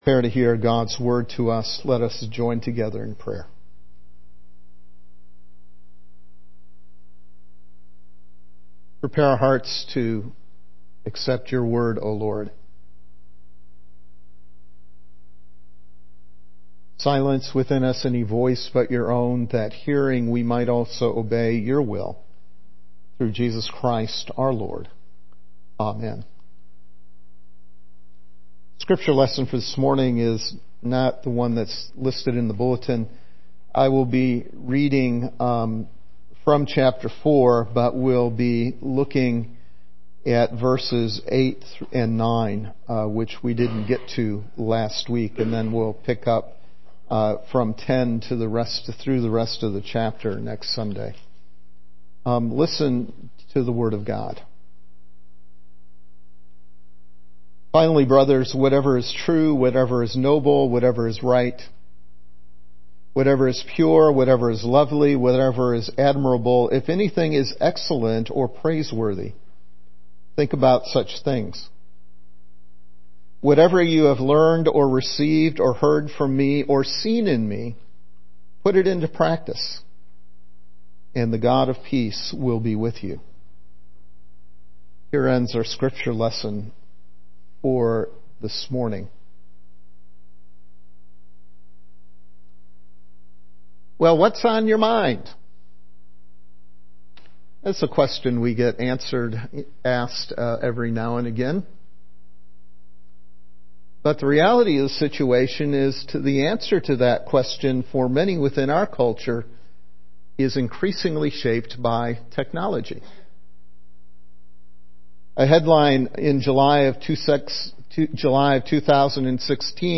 Philippians: Joy in All Things - Sermon From Calvary Presbyterian Church